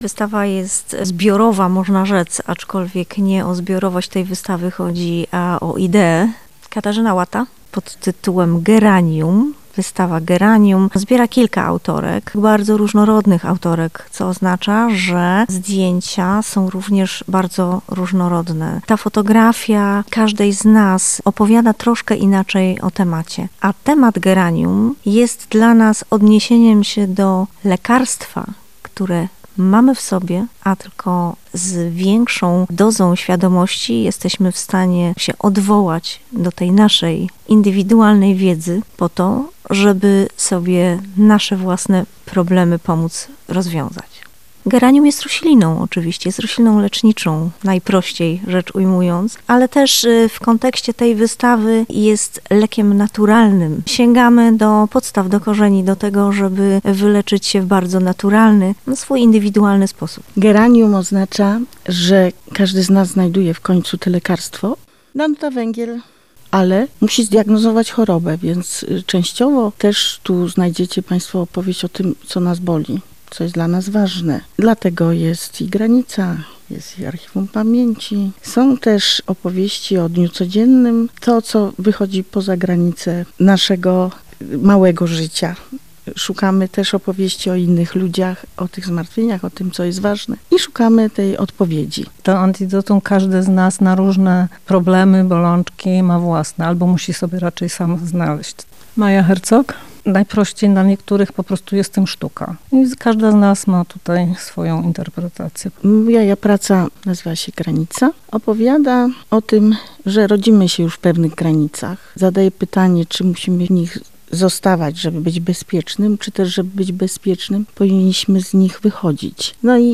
zapis audio wywiadu